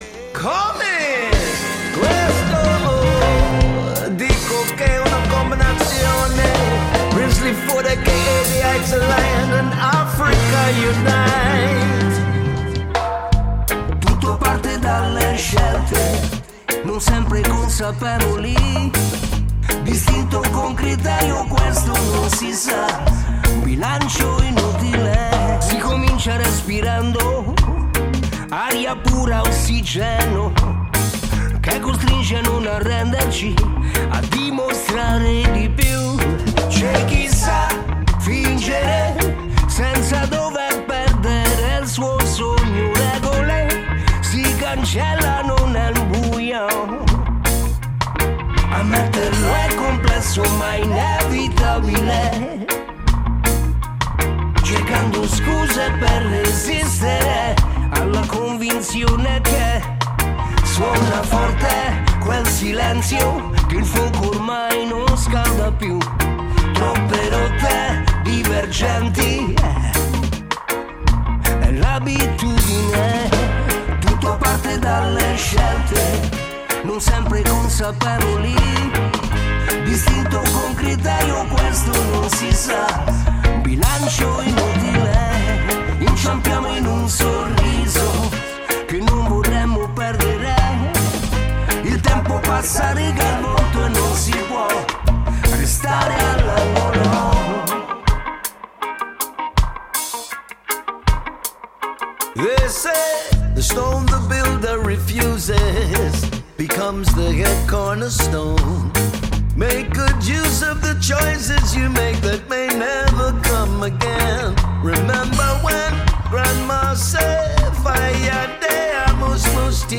ARTISTA A LA VISTA INTERVISTA LIVE | BUNNA DEGLI AFRICA UNITE | AL ROTOTOM SUNSPLASH | Radio Città Aperta
Abbiamo fatto una chiacchierata a caldo, subito dopo il concerto al Rototom Sunsplash 2022.
Artista-a-la-Vista-Intervista-Bunna-2022.mp3